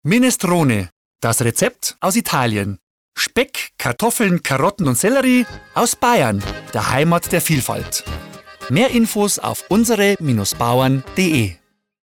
UBB_Radiospot_Minestrone.mp3